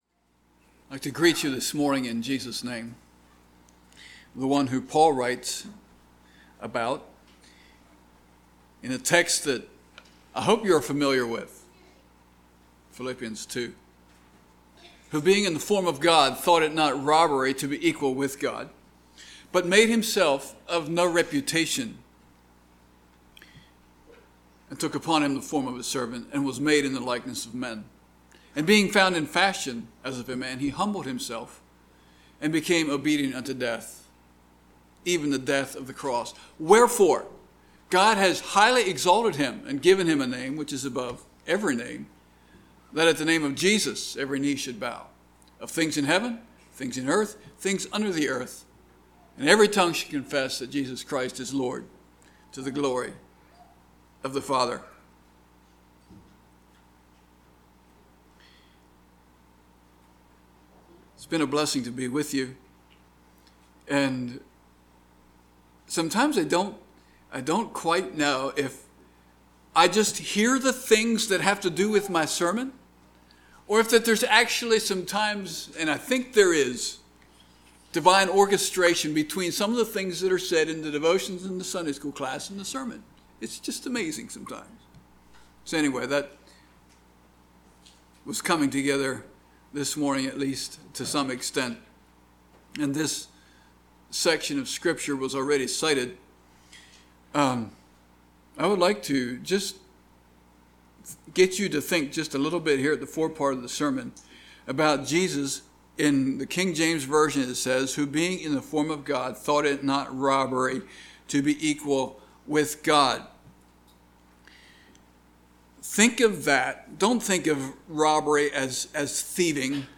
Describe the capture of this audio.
Passage: Genesis 11:1-9 Service Type: Sunday Morning Topics: God , God's Sovereignty